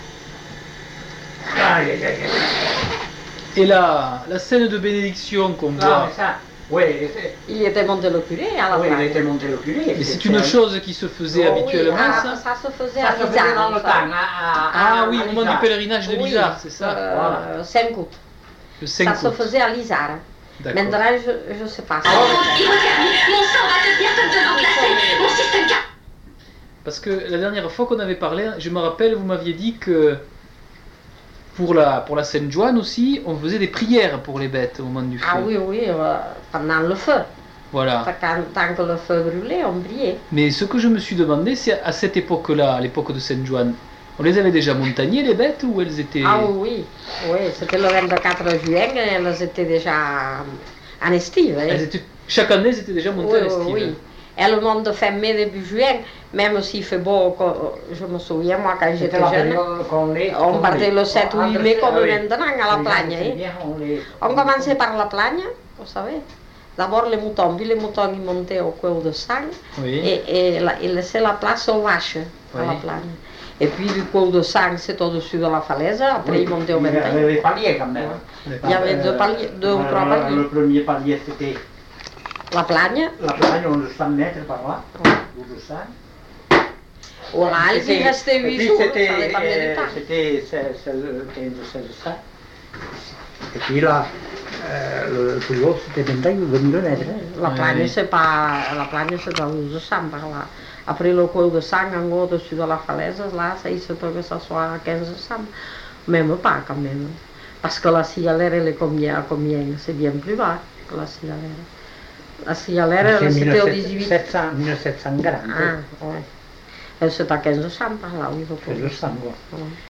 Aire culturelle : Couserans
Genre : témoignage thématique